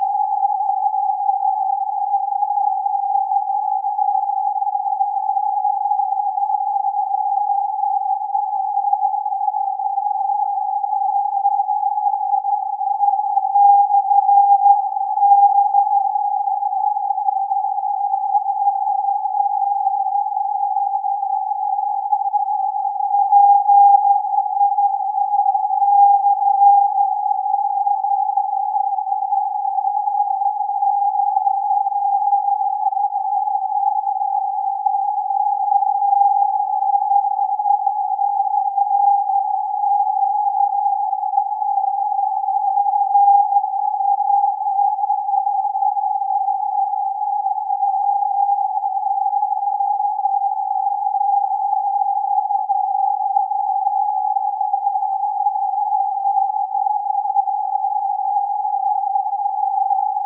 Here are clips from the first and the latest audible instances of "PIE/B FL" as copied in SE Kansas.
The June 7 Argo capture illustrates the approximately 2-3 minute QSB cycle that is common to 22 meters at certain times, while the June 19 MP3 is afflicted with the 3-6 second rapid-fading cycle that chopped up the ID into fragments that read like "/B" then "PI /B" and "L" or "FL" and so on.
Receive setup is a Kenwood TS-590 with TXCO reference, fed from the 40-foot top hatted vertical antenna without the usual buffer amp ahead of the coax.